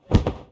Close Combat Swing Sound 22.wav